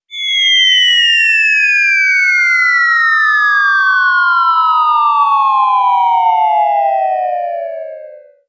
CartoonGamesSoundEffects
Falling_v4_wav.wav